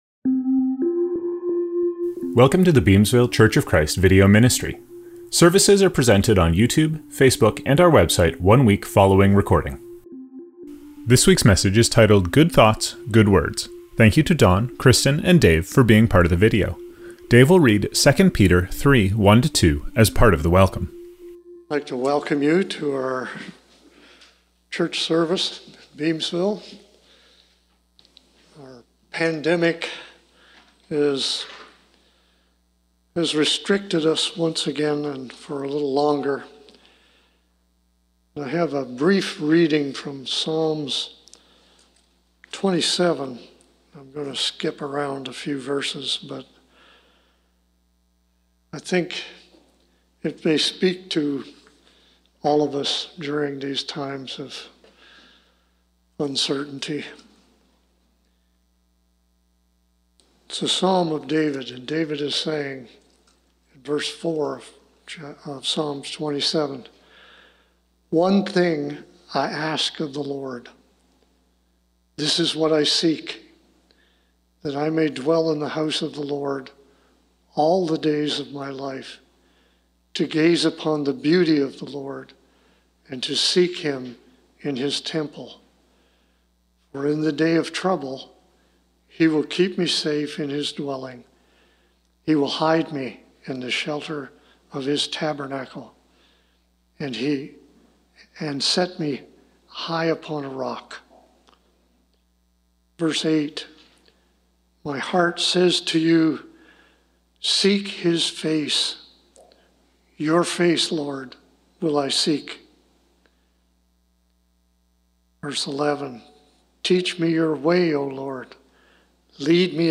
Scriptures from this service: Welcome - Psalm 27:4-5; 27:8; 27:11; 27:13-14; 2 Peter 3:1-2.